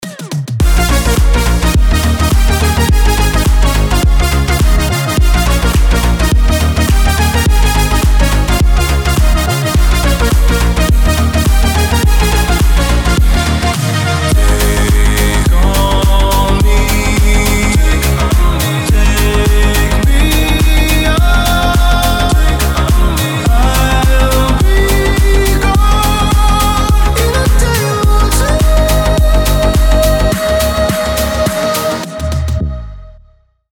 • Качество: 320, Stereo
мужской вокал
громкие
remix
веселые
Cover
electro house